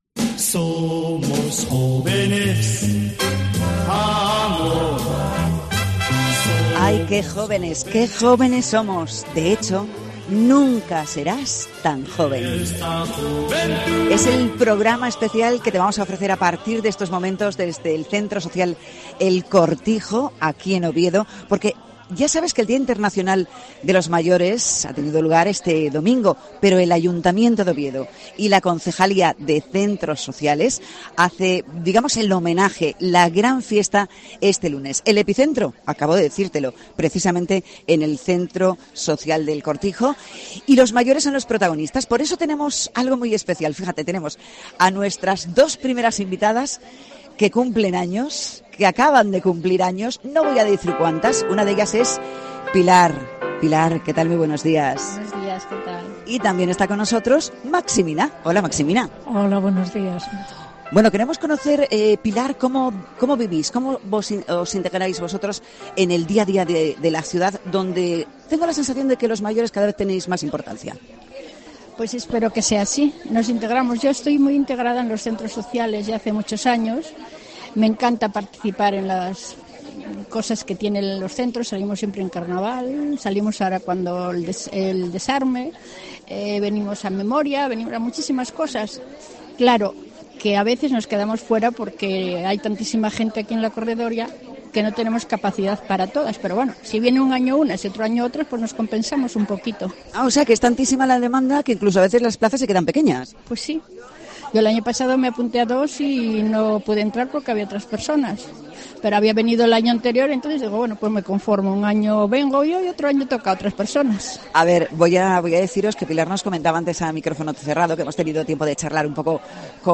Hemos emitido, este lunes, una programación especial, junto al área de Centros Sociales de Oviedo, desde el centro de El Cortijo, en La Corredoria